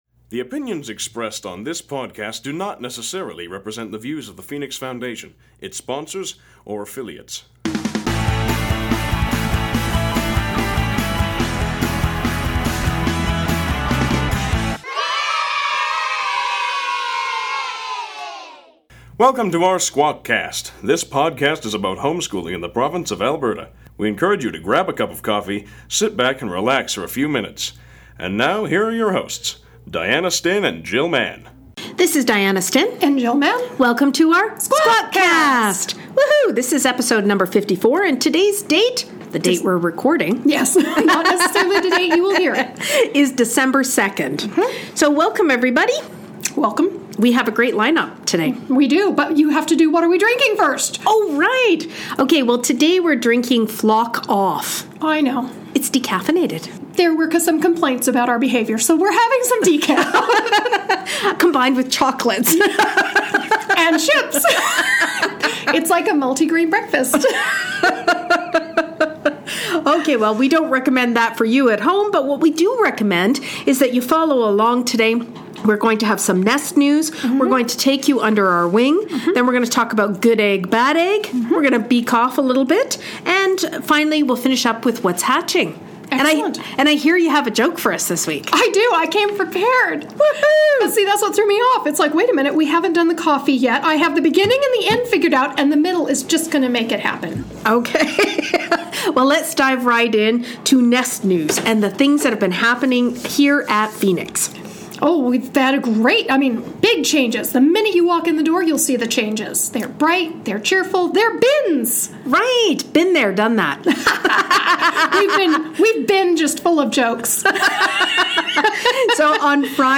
deep voiced intro and close!